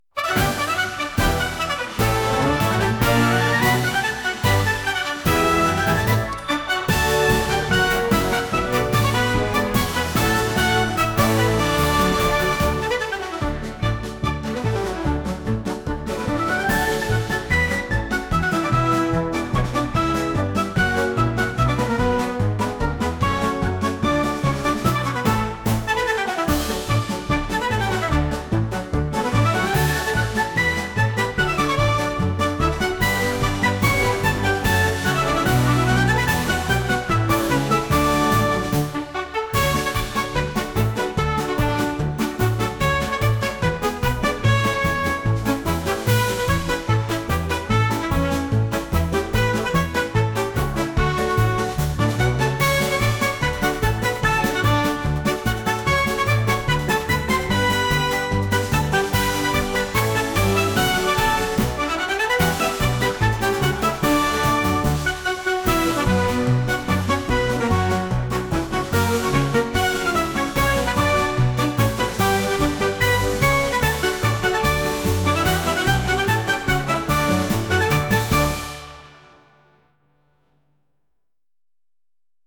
陽気になりたいような音楽です。